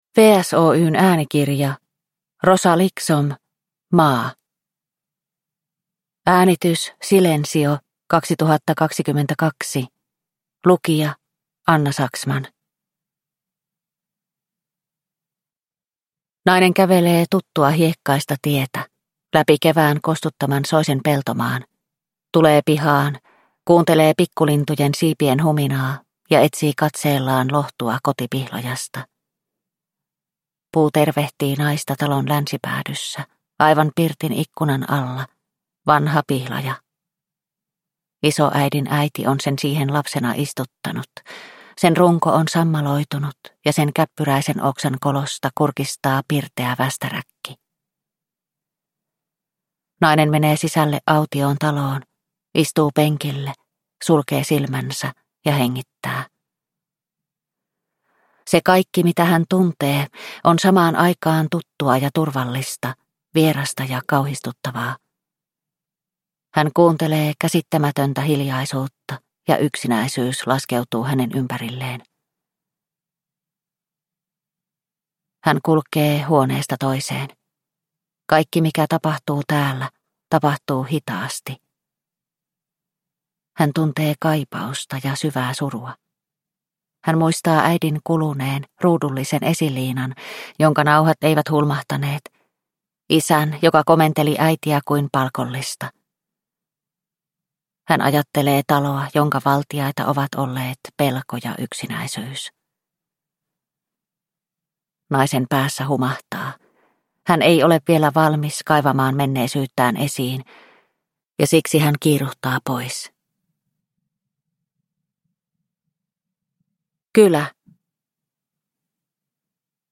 Maa – Ljudbok – Laddas ner